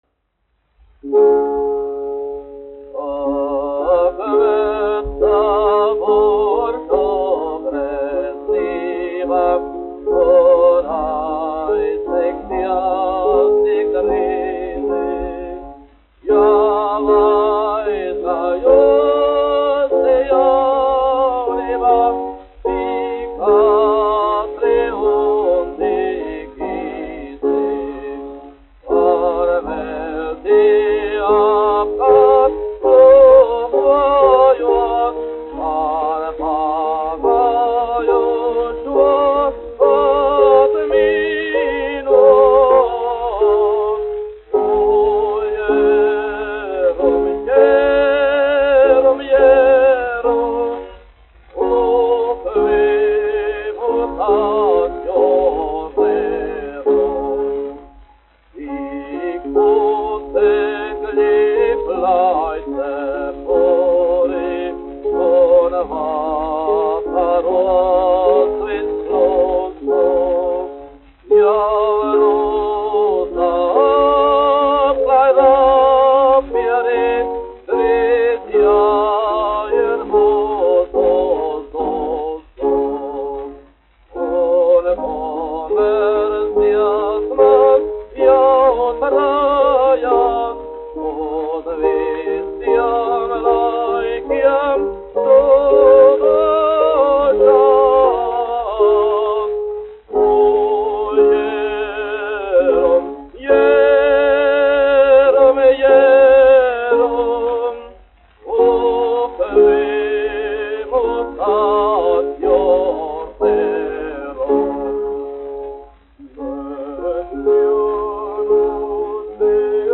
1 skpl. : analogs, 78 apgr/min, mono ; 25 cm
Dziesmas (vidēja balss)
Skaņuplate
Latvijas vēsturiskie šellaka skaņuplašu ieraksti (Kolekcija)